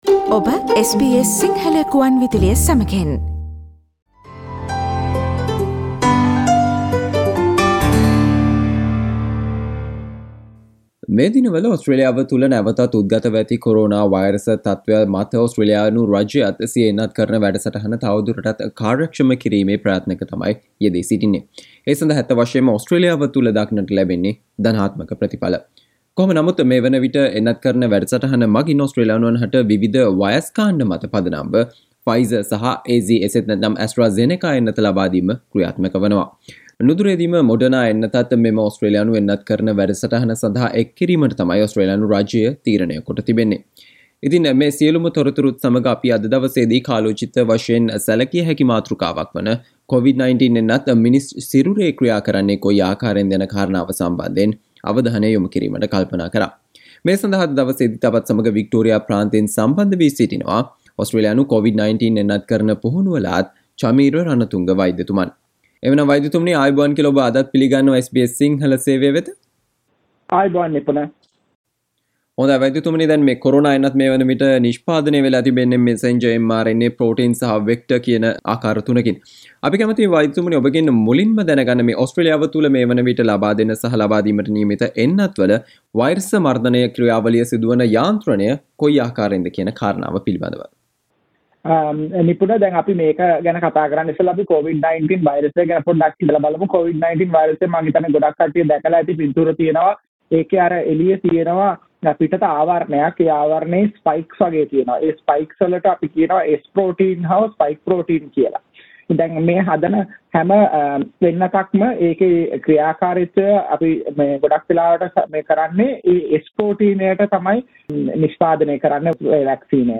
කොරෝනා එන්නත ගැනීමෙන් පසුව ඔබගේ සිරුර තුල සිදුවන දෑ පිළිබඳව SBS සිංහල සේවය ගෙන එන සාකච්චාවට සවන්දෙන්න